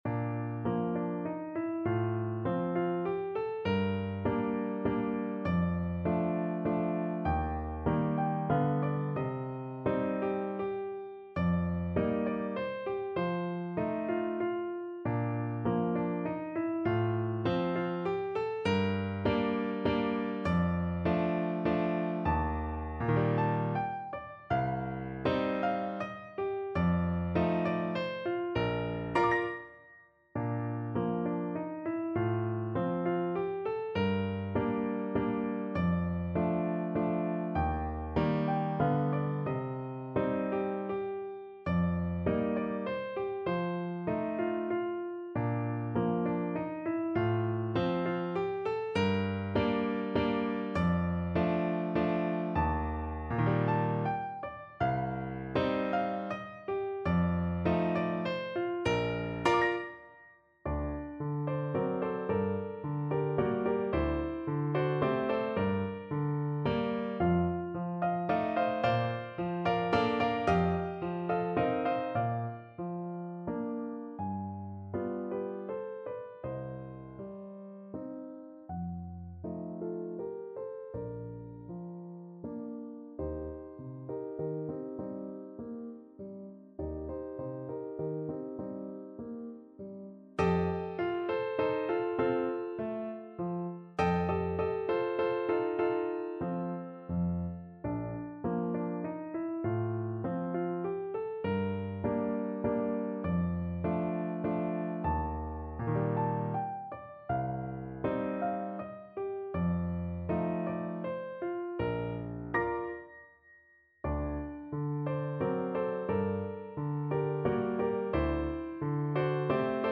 No parts available for this pieces as it is for solo piano.
Bb major (Sounding Pitch) (View more Bb major Music for Piano )
3/4 (View more 3/4 Music)
~ = 100 Tranquillamente
Piano  (View more Intermediate Piano Music)
Classical (View more Classical Piano Music)